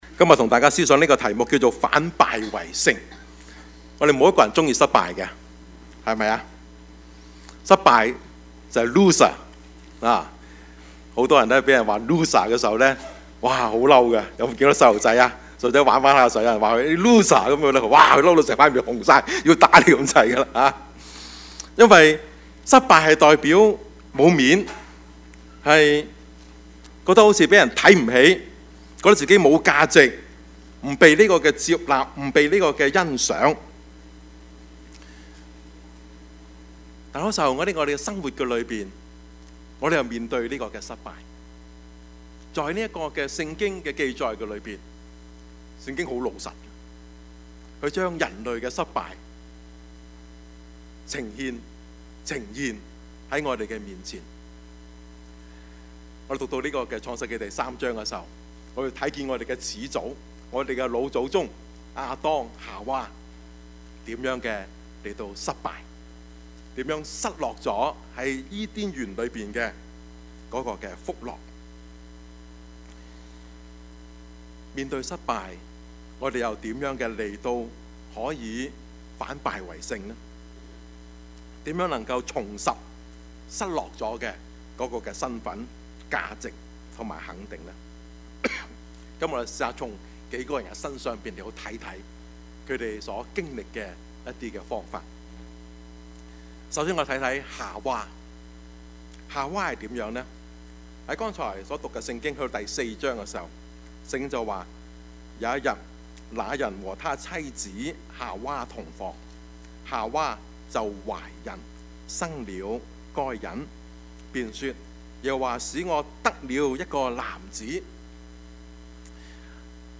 Passage: 創世記 4:1-8; 羅馬書 7:18-25 Service Type: 主日崇拜
Topics: 主日證道 « 馬禮遜（二） 一個良好的政府 »